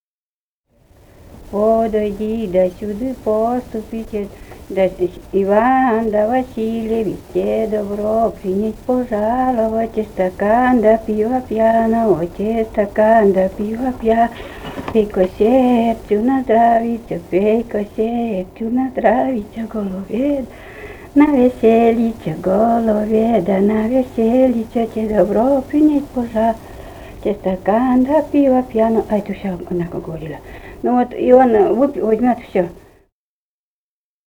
«Подойди досюда, поступи» (свадебная) — комм.: «едут от жениха сватать» (РФ.